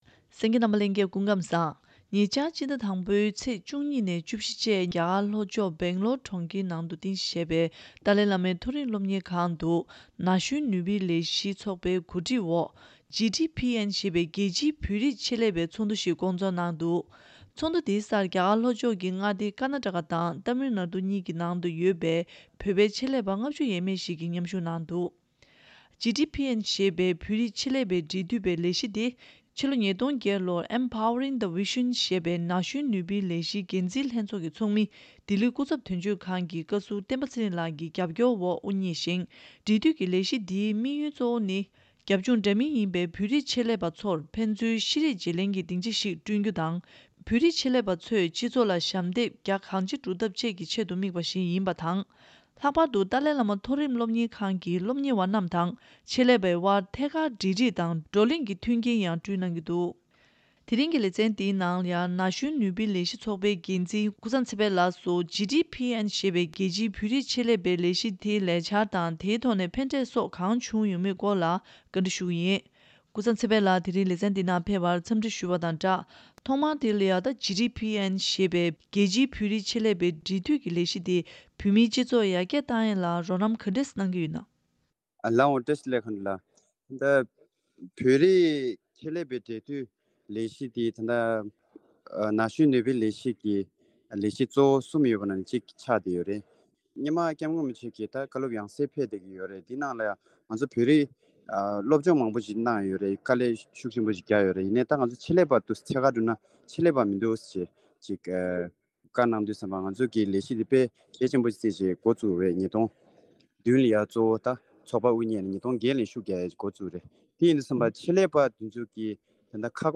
བཅར་འདྲིའི་ལེ་ཚན